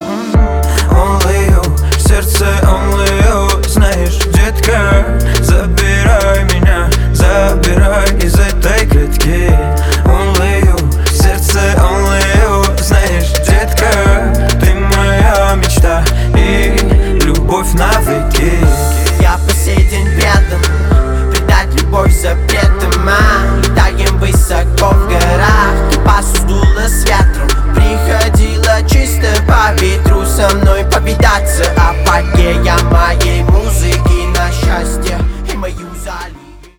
• Качество: 275, Stereo
мужской голос
пианино